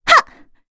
FreyB-Vox_Attack3.wav